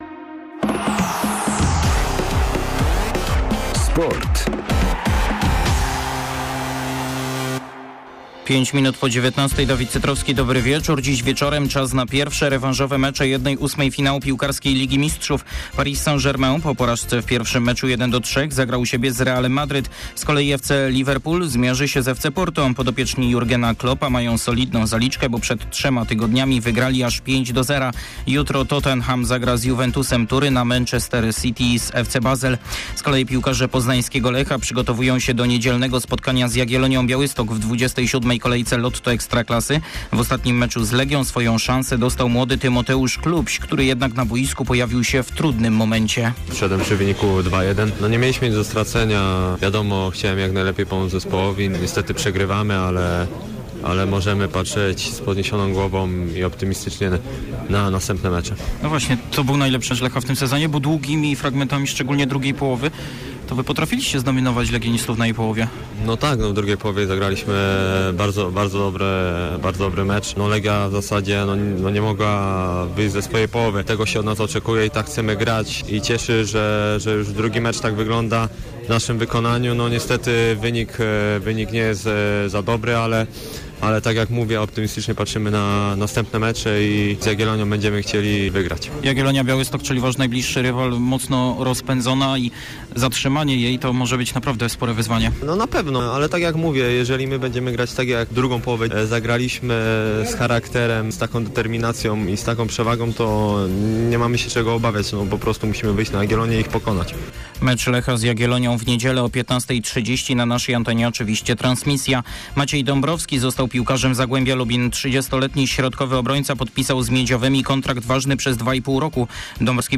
06.04 serwis sportowy godz. 19:05